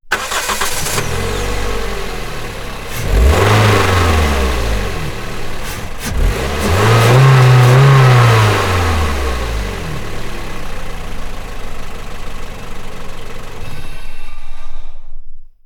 Acelerones del motor de un coche RAV4